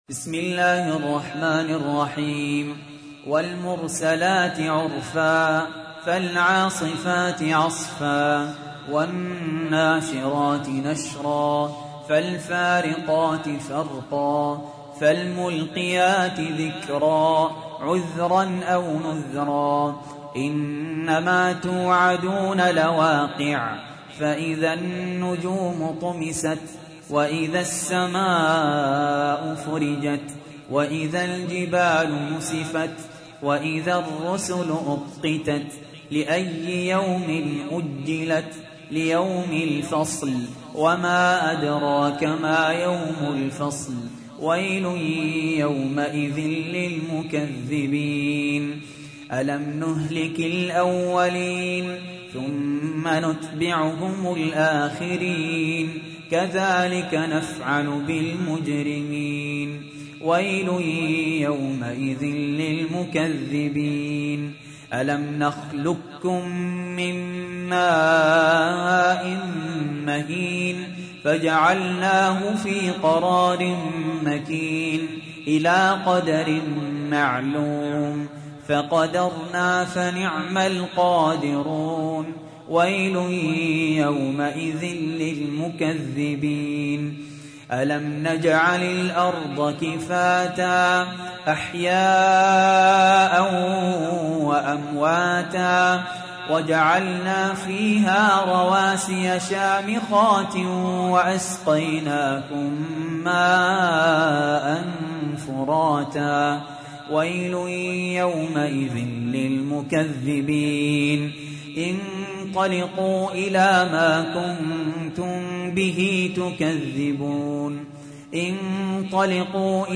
تحميل : 77. سورة المرسلات / القارئ سهل ياسين / القرآن الكريم / موقع يا حسين